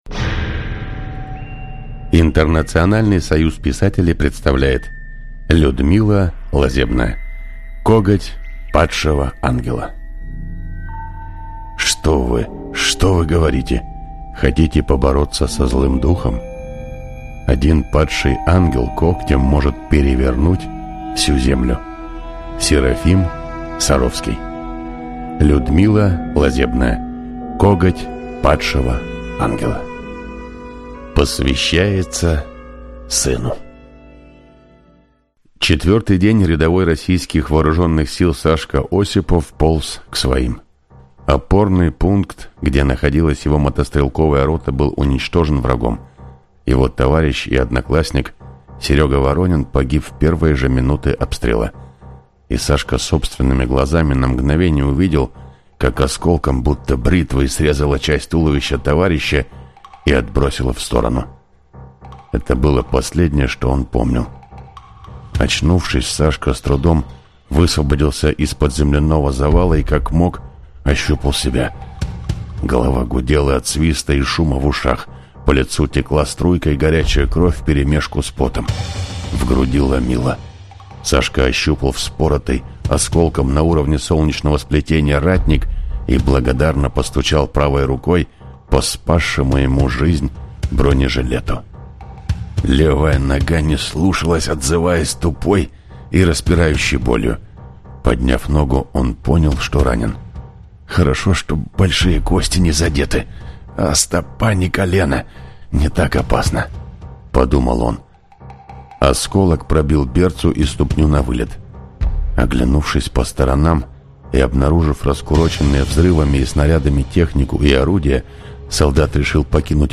Аудиокнига Коготь падшего ангела | Библиотека аудиокниг